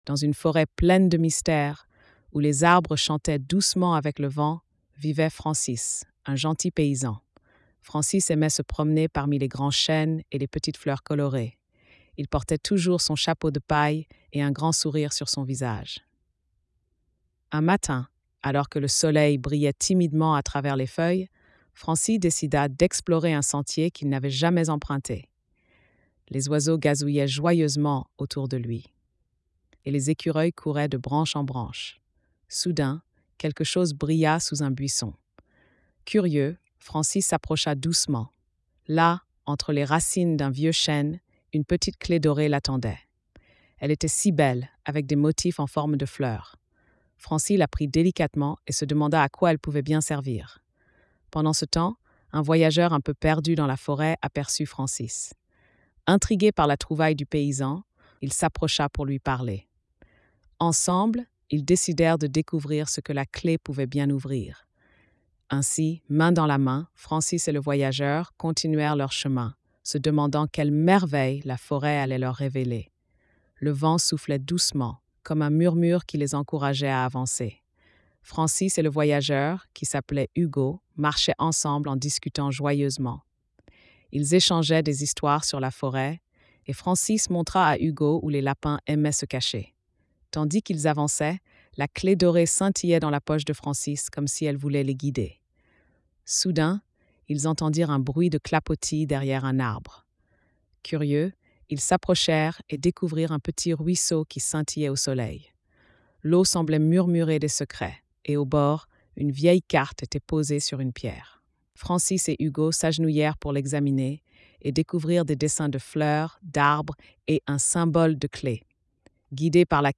🎧 Lecture audio générée par IA